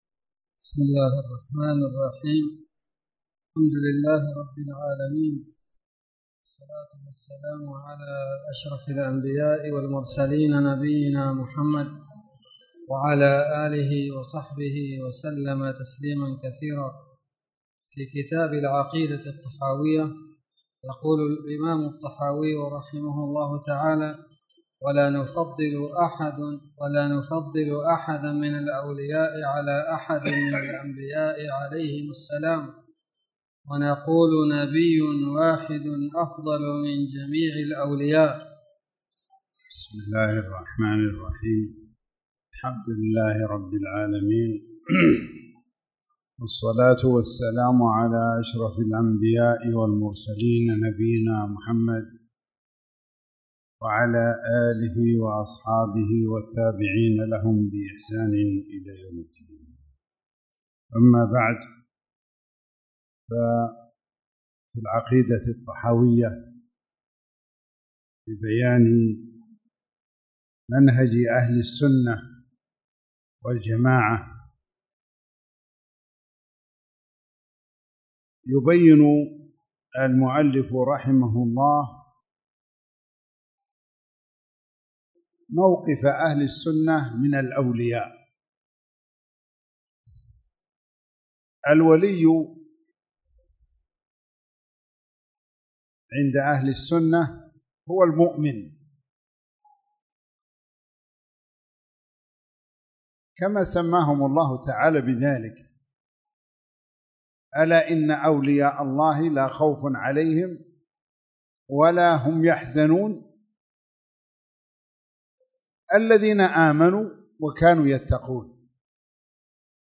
تاريخ النشر ٤ ربيع الثاني ١٤٣٨ هـ المكان: المسجد الحرام الشيخ